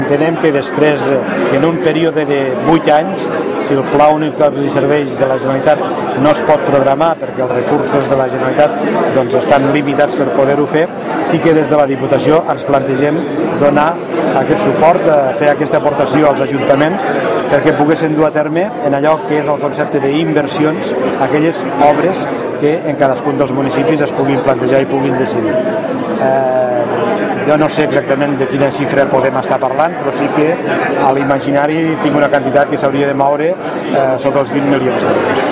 El president de la Diputació de Lleida ha explicat les prioritats i les línies bàsiques del pressupost per al 2016, en la tradicional trobada nadalenca amb la premsa